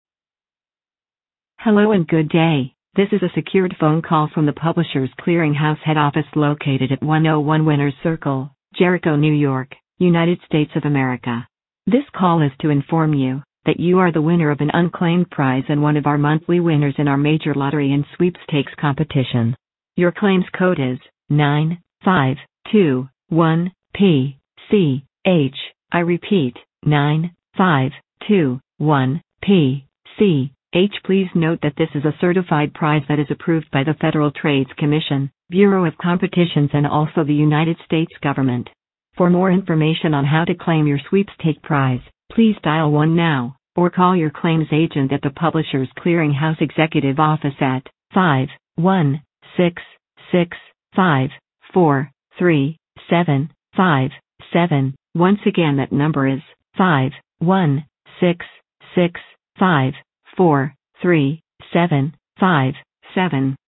Robocall :arrow_down: